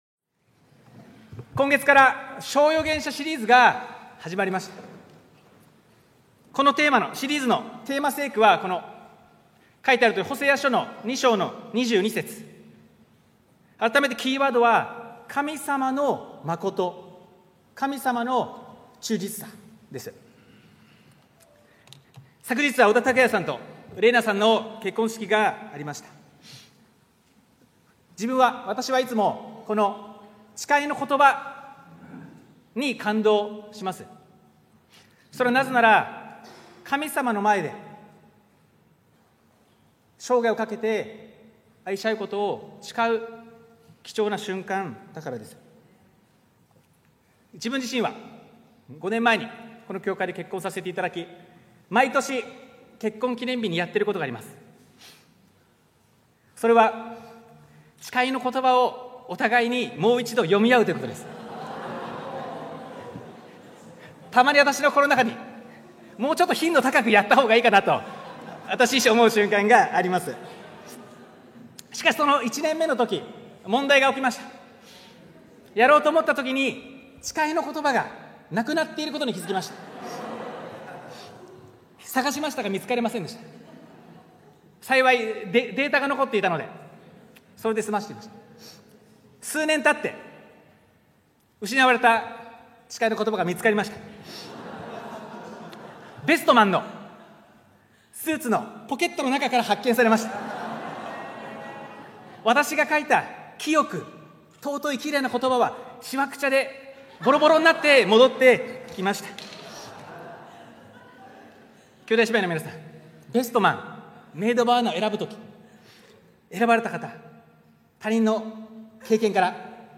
東京キリストの教会 日曜礼拝説教